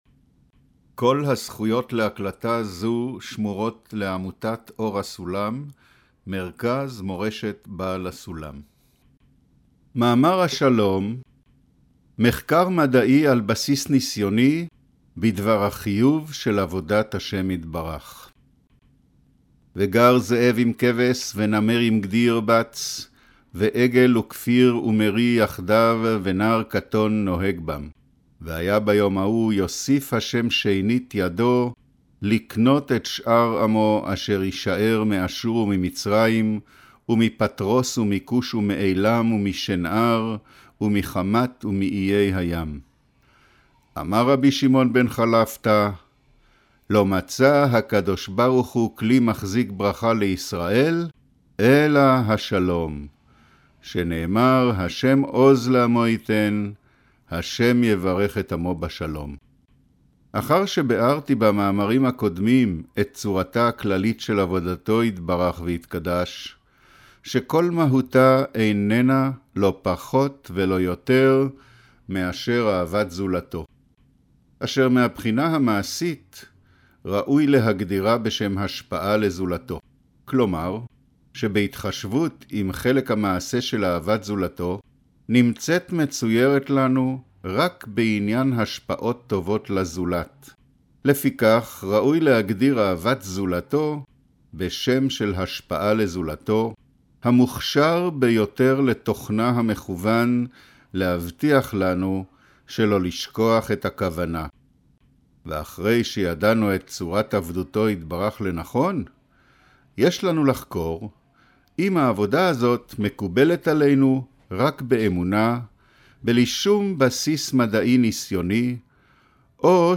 אודיו - קריינות השלום